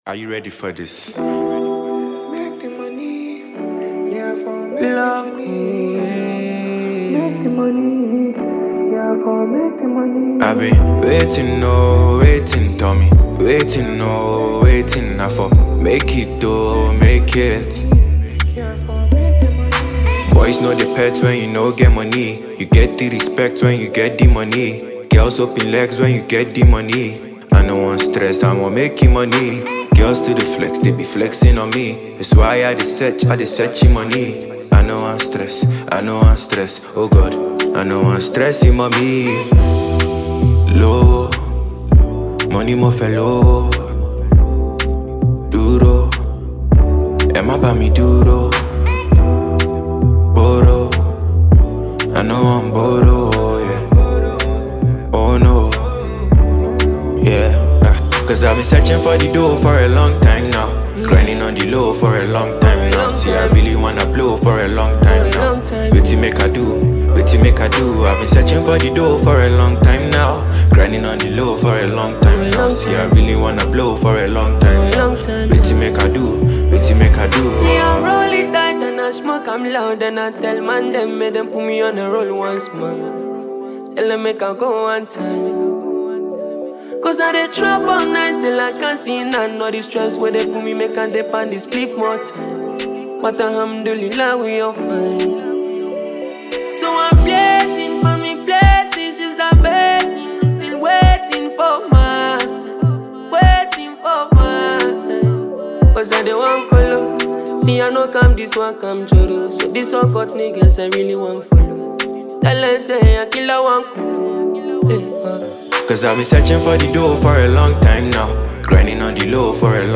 Ghana Music Music
Multi-talented singer